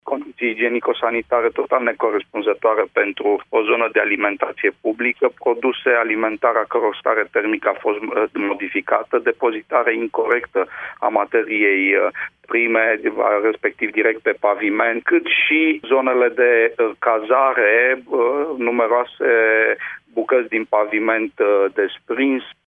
Acestea sunt condiţiile în care sunt ţinute zeci de persoane vârstnice sau cu handicap, susţine şeful Comisariatului Regional pentru Protecţia Consumatorilor, Sorin Susanu: